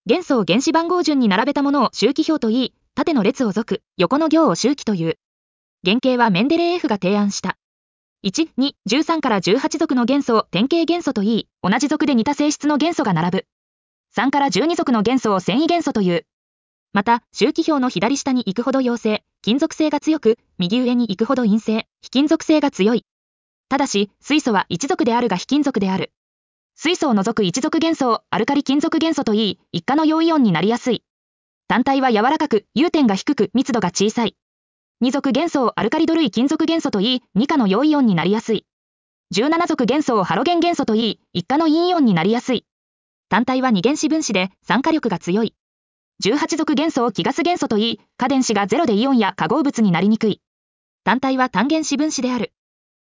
• 耳たこ音読では音声ファイルを再生して要点を音読します。
ナレーション 音読さん